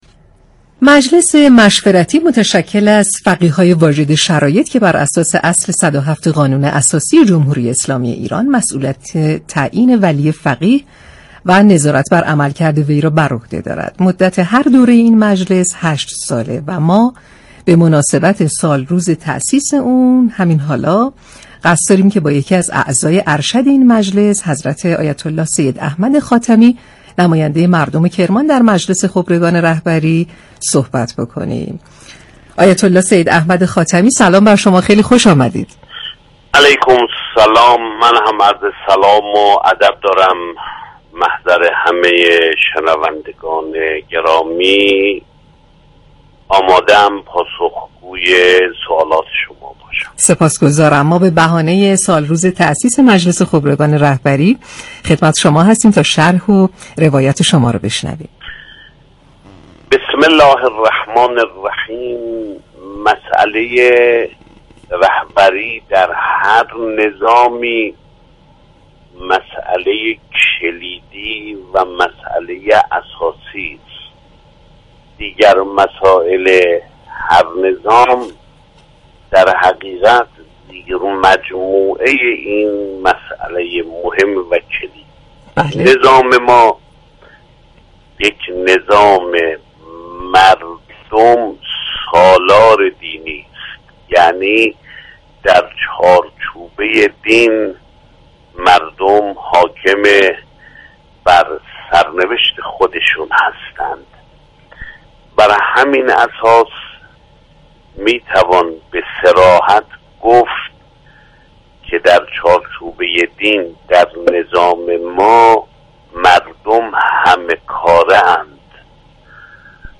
به گزارش پایگاه اطلاع رسانی رادیو تهران، سید احمد خاتمی عضو مجلس خبرگان رهبری در گفت‌و‌گو با شهر آفتاب رادیو تهران گفت: مساله رهبری در هر نظامی مساله كلیدی و اساسی است و دیگر مسائل هر نظام زیر مجموعه مساله رهبری است.